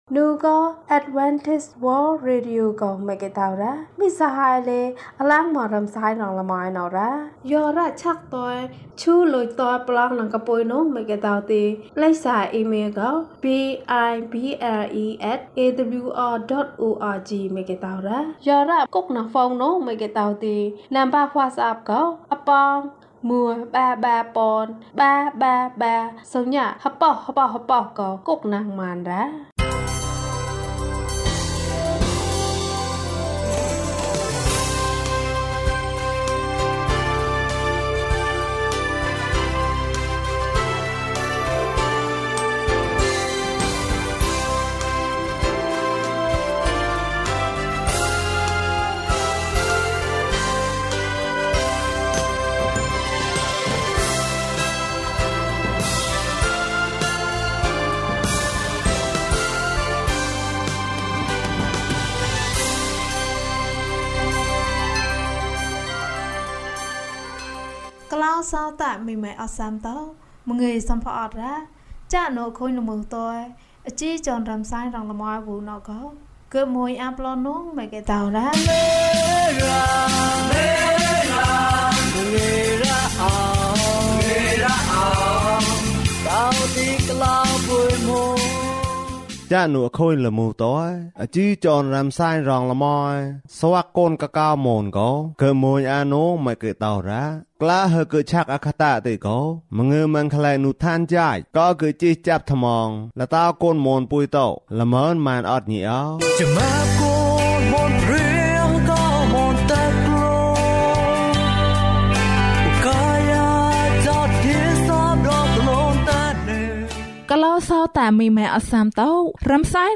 ခရစ်တော်ဆီသို့ ခြေလှမ်း။၅၆ ကျန်းမာခြင်းအကြောင်းအရာ။ ဓမ္မသီချင်း။ တရားဒေသနာ။